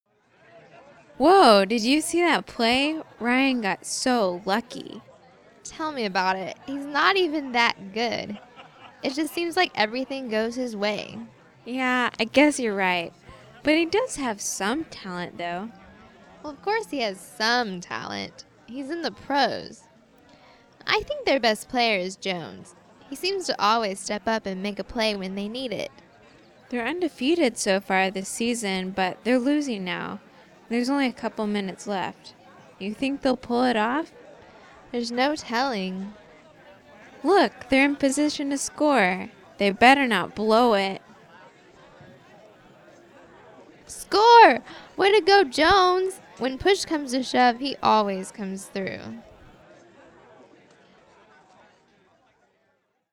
Conversaciones en Inglés
Audio – Versión Lenta
watching_sports_slow.mp3